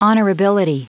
Prononciation du mot honorability en anglais (fichier audio)
Prononciation du mot : honorability